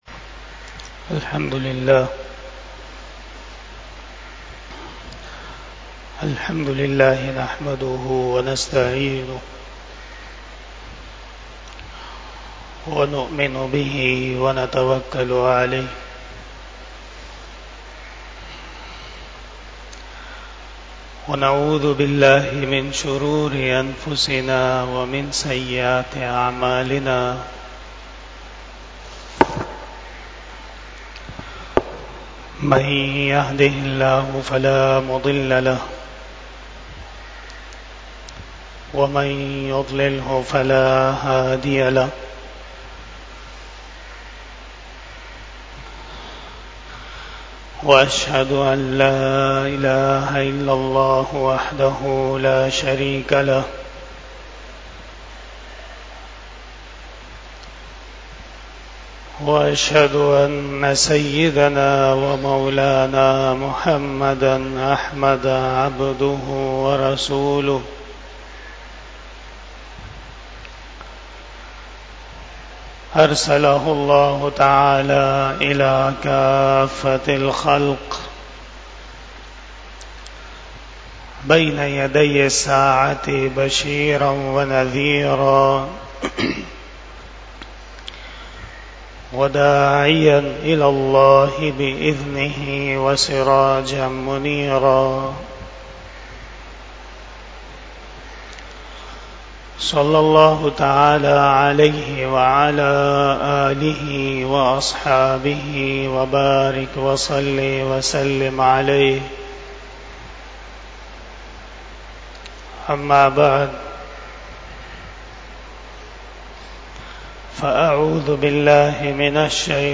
25 Bayan E Jummah 21 June 2024 (14 ZilHajjah 1445 HJ)
بیان جمعۃ المبارک 21 جون 2024ء بمطابق 14 ذی الحجہ 1445ھ